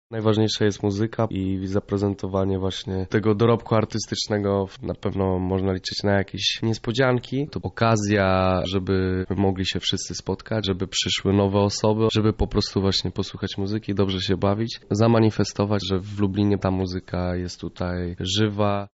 „Tutaj najważniejsza jest dobra zabawa”, mówi jeden z organizatorów imprezy